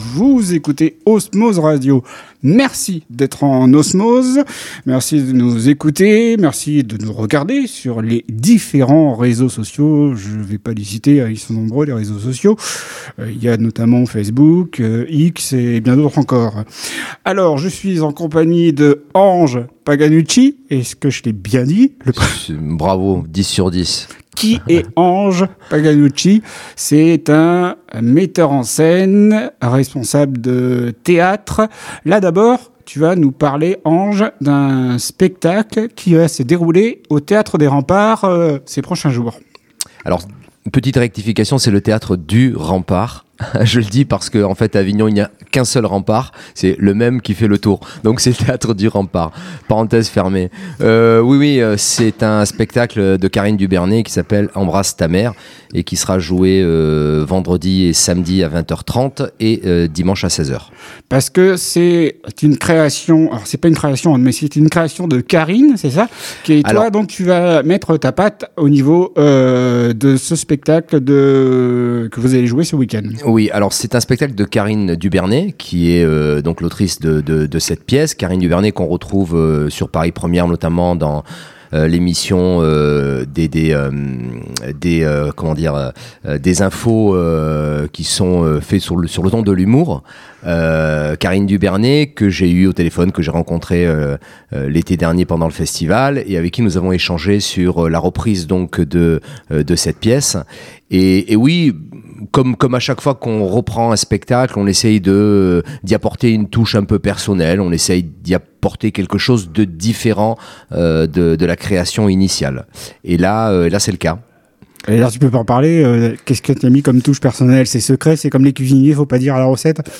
Culture/Loisirs Interviews courtes